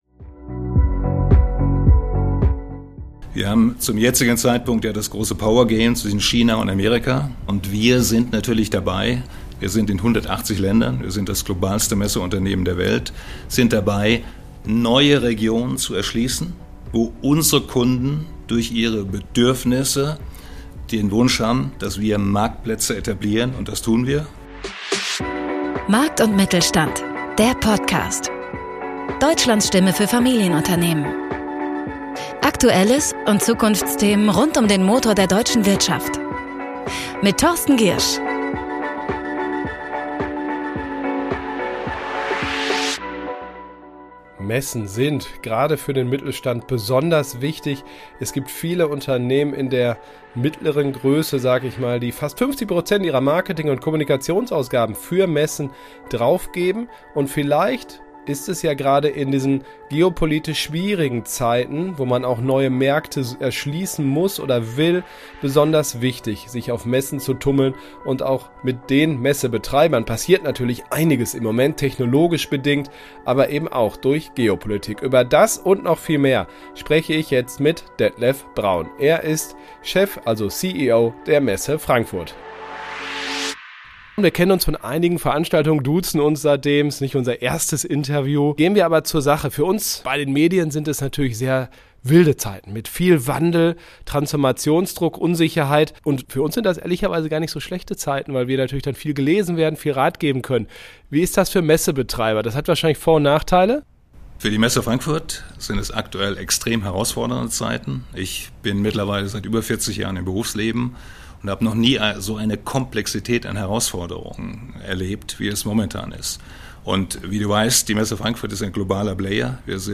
Ein Gespräch über Transformation, Internationalisierung und die Zukunft echter Begegnungen.